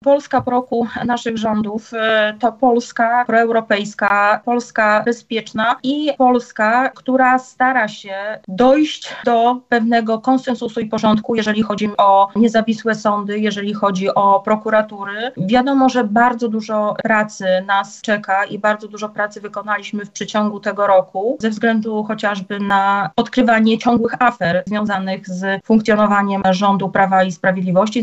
– Wyciągnęliśmy wnioski z poprzednich kampanii wyborczych, a rola Polski powiatowej jest ogromna – mówiła na antenie Radia Lublin posłanka Platformy Obywatelskiej, Małgorzata Gromadzka.